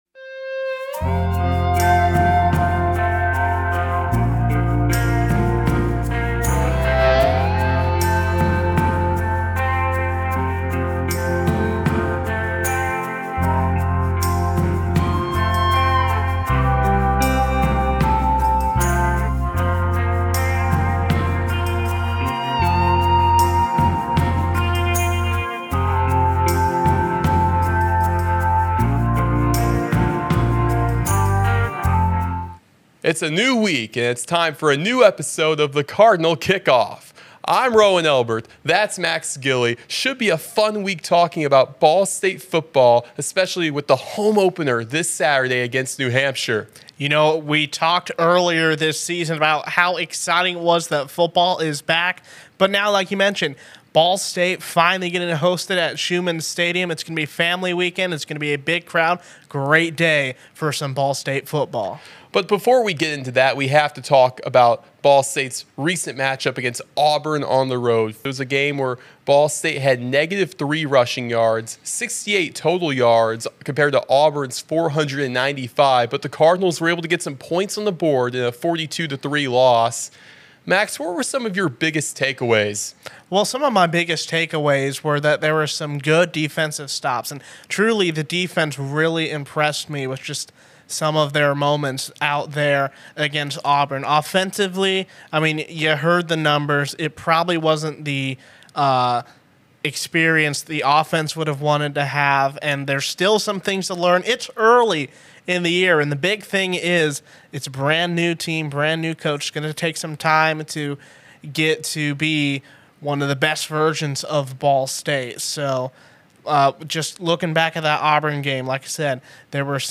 Each week go inside Ball State Football with exclusive interviews, feature stories, and discussion.